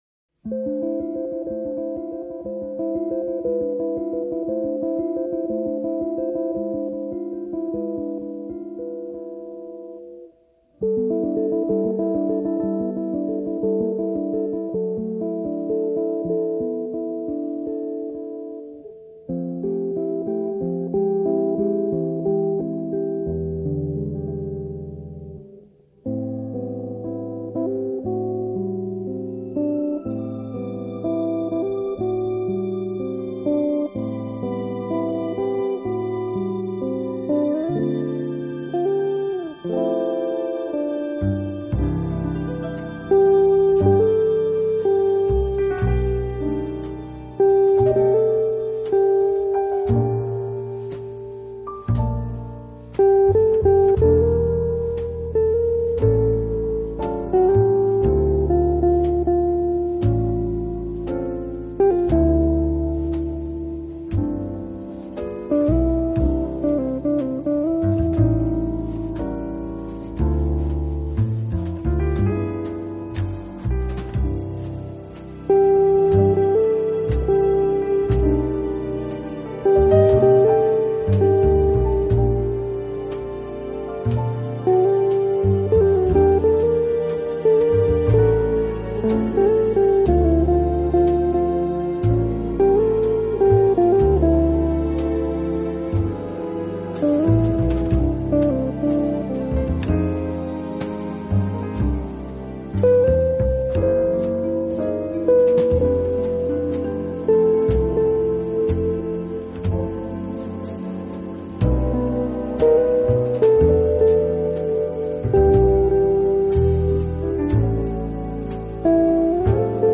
【爵士吉它】
风格：Mainstream Jazz, Smooth Jazz